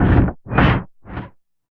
81 NOISE 2-R.wav